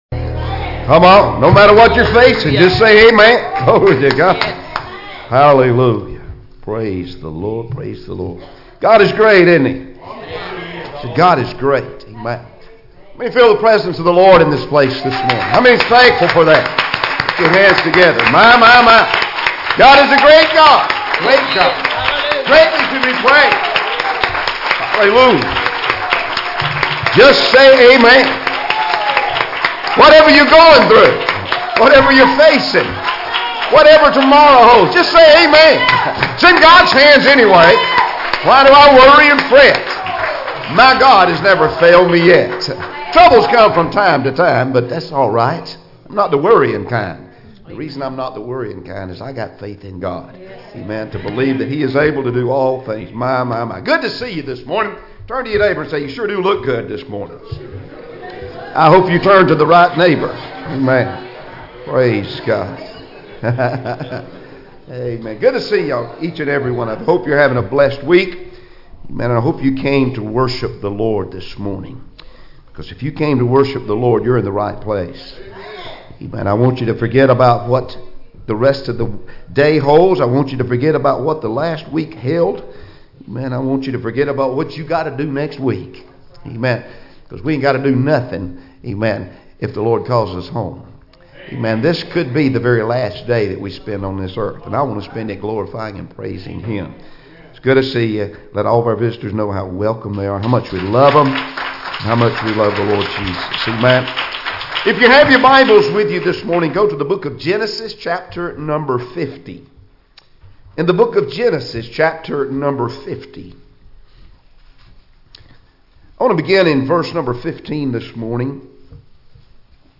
Passage: "Genesis 50:15-21" Service Type: Sunday Morning Services « Love